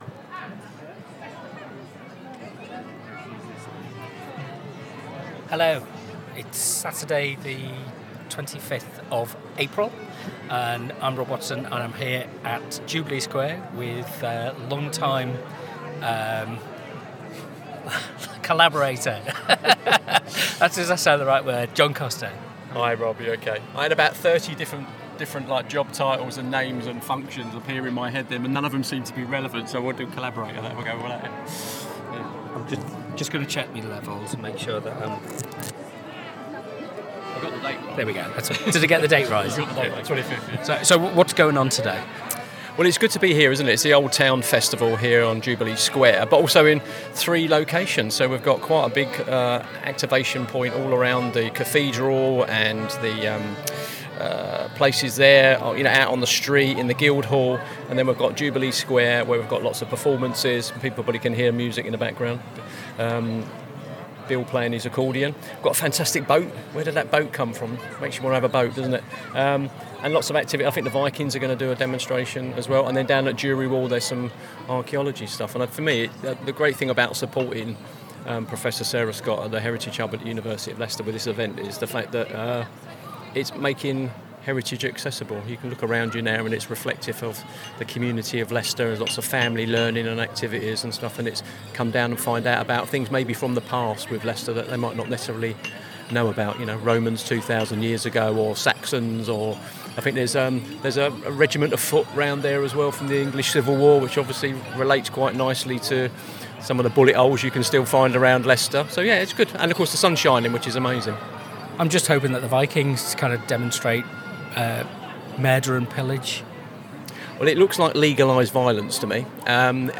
The accompanying podcast captures this movement in real time. Conversations unfold not as formal interviews but as exchanges rooted in curiosity, humour, and observation.